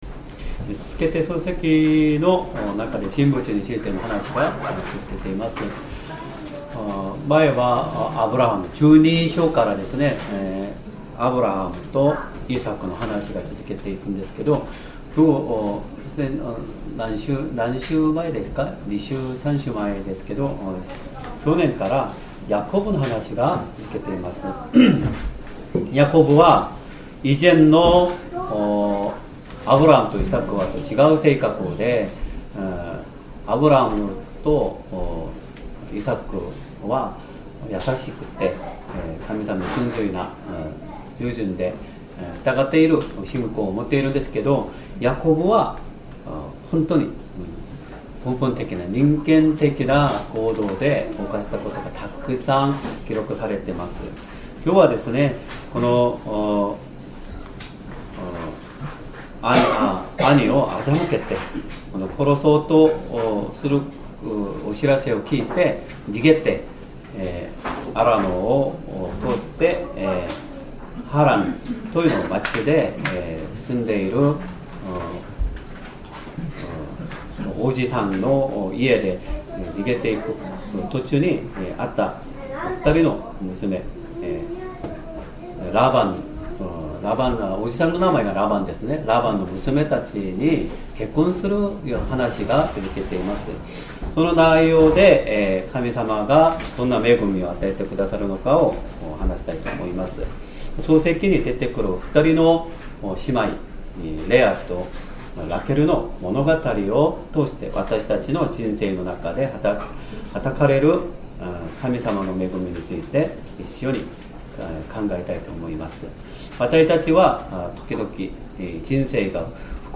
Sermon
2026年1月11日 主日礼拝 説教 「神様はレアを見た 」 聖書 創世記29章 31～35節 29:31 【主】はレアが嫌われているのを見て、彼女の胎を開かれたが、ラケルは不妊の女であった。